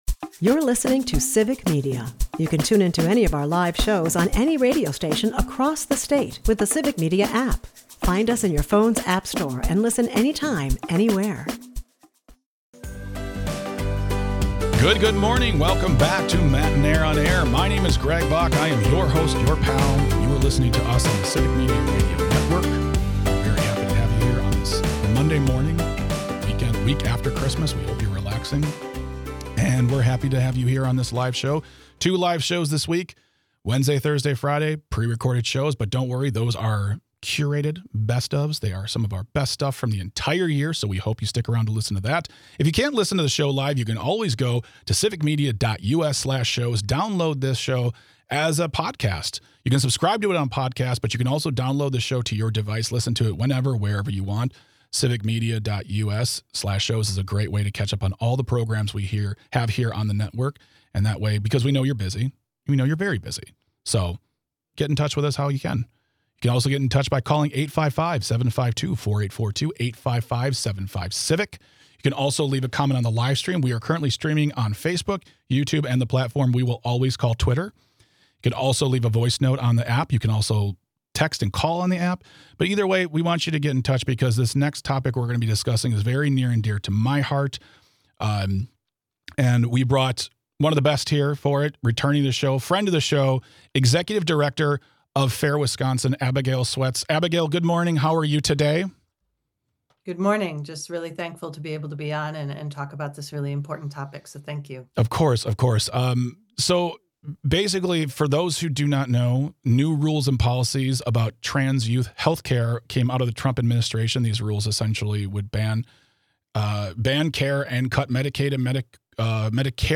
For Audio Sorbet, we hear from our listeners and get their thoughts on the show and the topics we've discussed thus far and finally, we round it all out with This Shouldn't Be A Thing - You Dirty Rat Edition .
Matenaer On Air is a part of the Civic Media radio network and airs weekday mornings from 9-11 across the state.